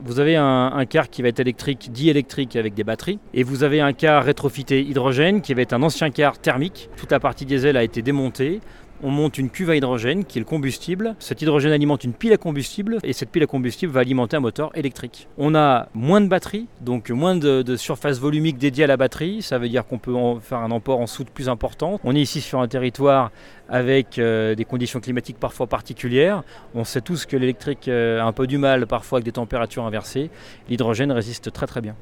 Le conseiller régional aux transports interurbains Julien Vuillemard explique l'intérêt de l'hydrogène.